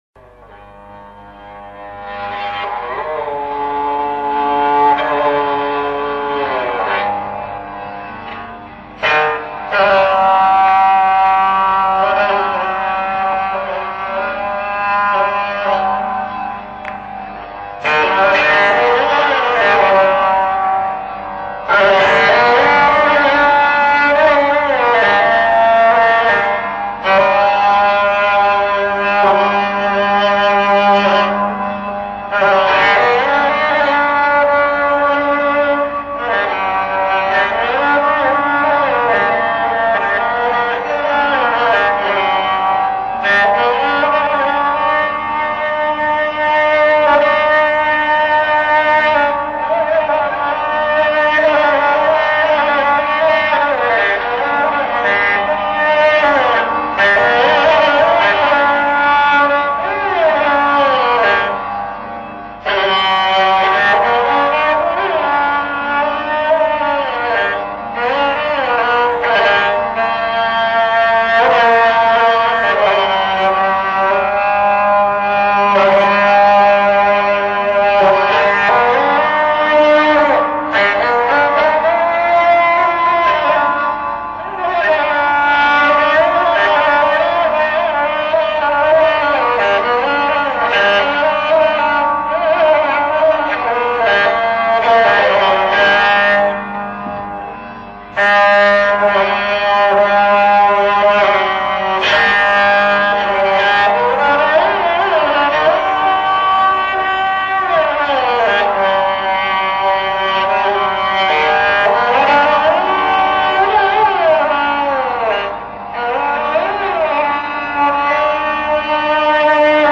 Madhuvanti (Instrument: Dilruba)